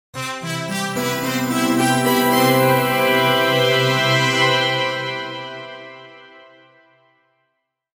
Winning Brass Fanfare Logo
Description: Winning brass fanfare logo.
Sound logo or intro music.
Genres: Sound Logo